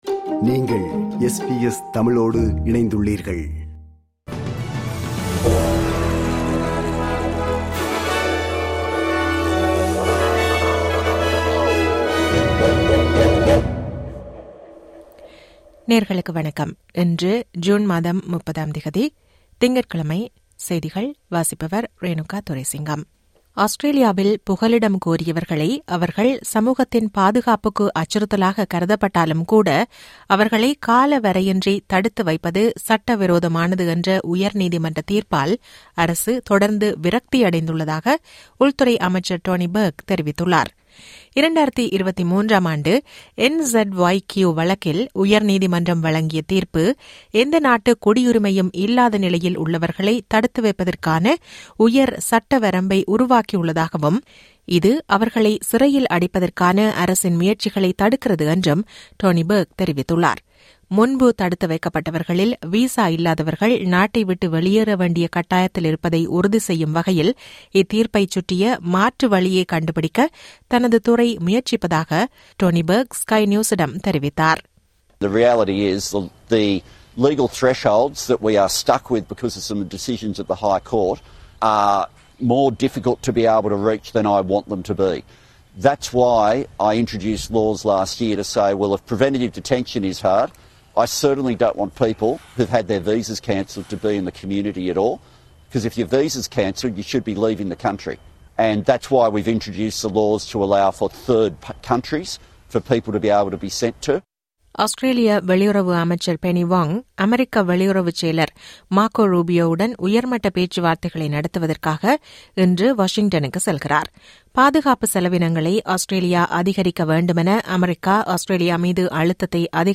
SBS தமிழ் ஒலிபரப்பின் இன்றைய (திங்கட்கிழமை 30/06/2025) செய்திகள்.